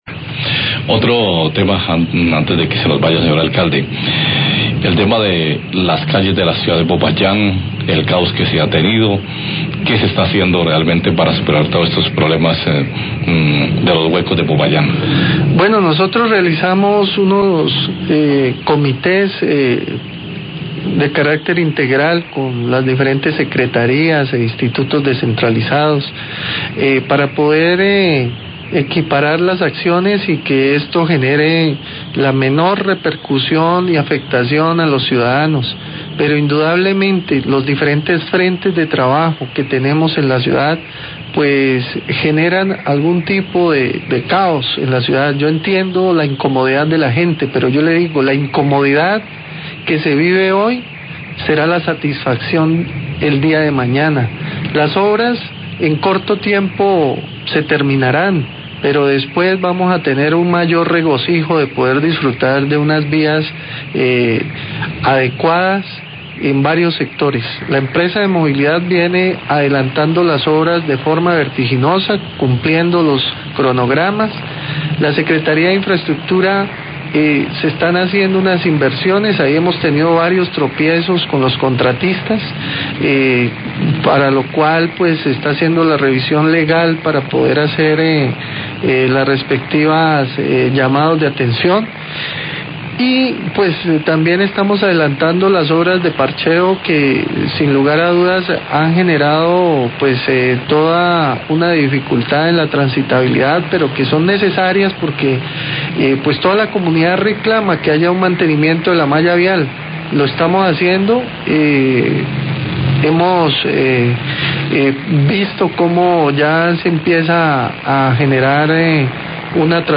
Radio
En los trabajos de Movilidad Futura para la implementación del Setp en la Transversal Novena, vía al Bosque, se tuvo una dificultad inicial con el traslado de los postes de energía, pero ya se han venido superando. Declaraciones de Cesar Cristian Gómez, Alcalde de Popayán.